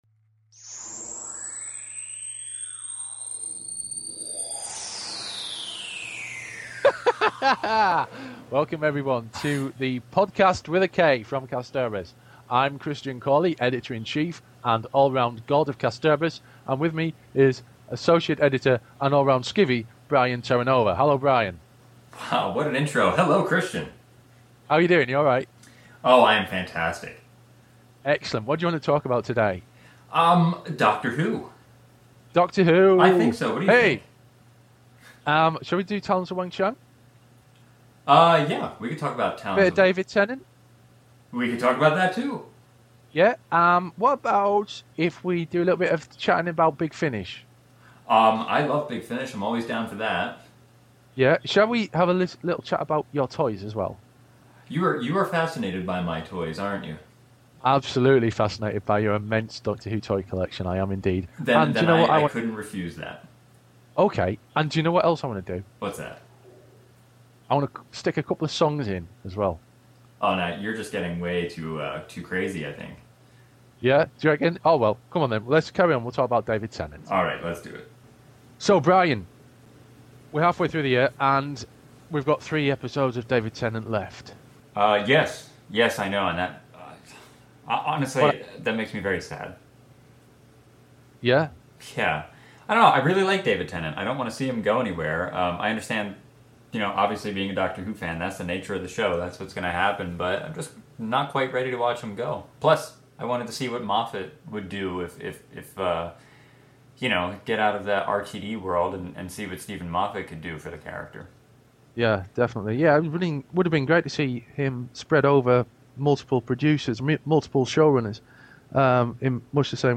This time however we've gone for a straighter approach underpinned by our usual jovial and irreverent look at Doctor Who and even peppered the material with music! It's a bit like a Doctor Who radio show!